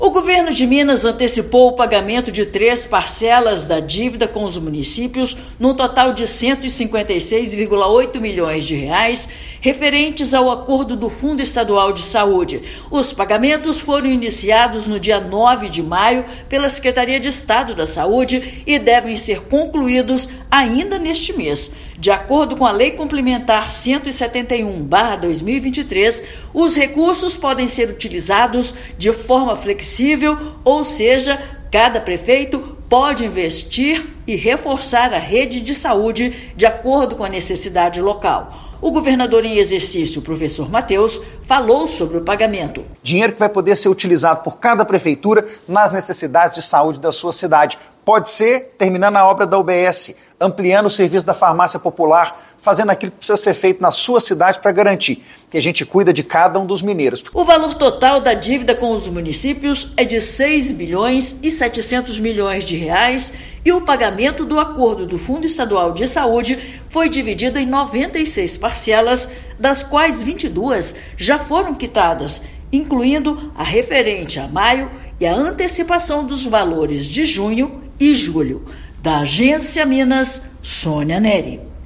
Pagamentos de maio, junho e julho tiveram início nesta semana e devem ser concluídos ainda neste mês. Ouça matéria de rádio.